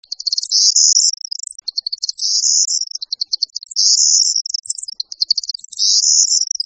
En cliquant ici vous entendrez le chant du Bruant Proyer